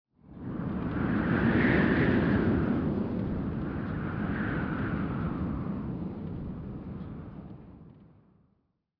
windgust5.wav